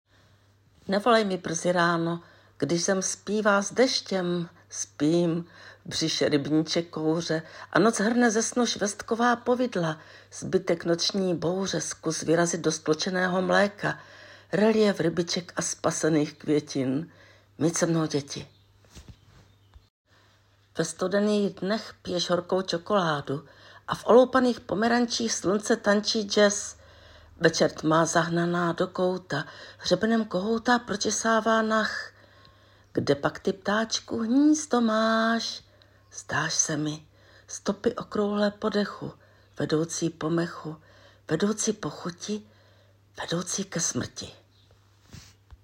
*** máš nádhernou barvu hlasu a ach, jak zpíváš!